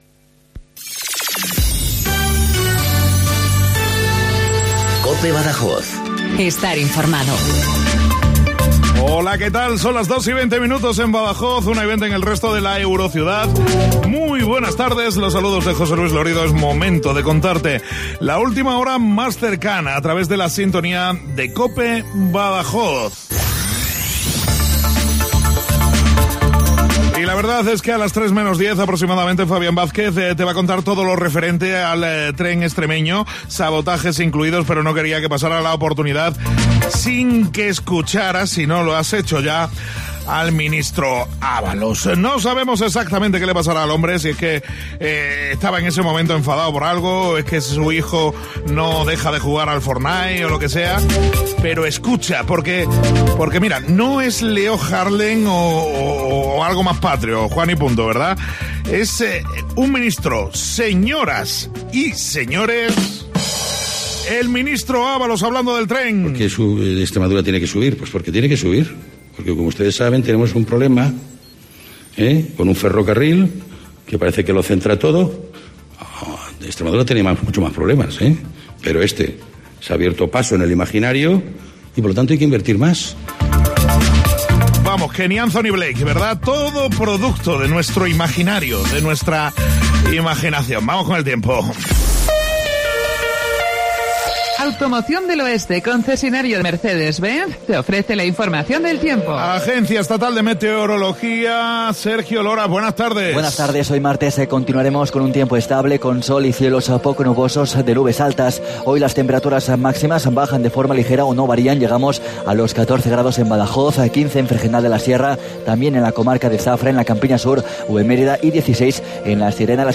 INFORMATIVO LOCAL BADAJOZ 14:20